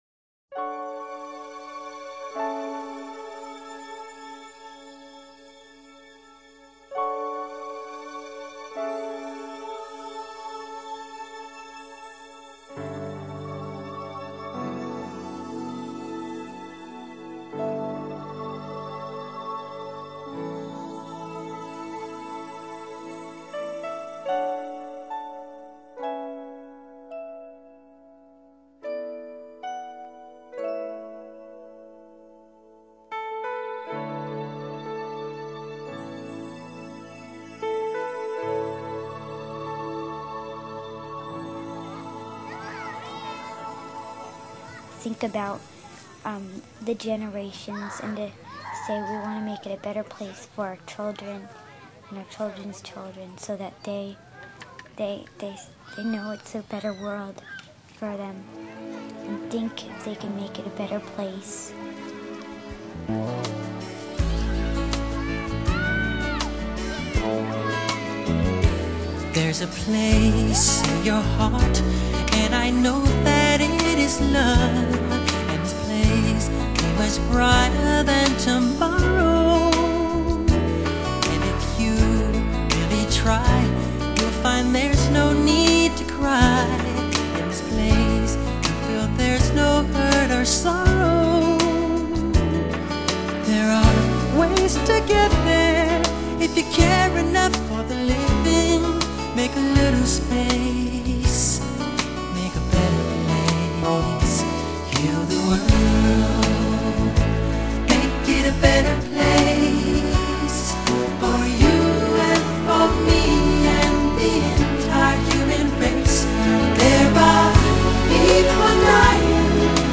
Genre: Rock Ballads, Pop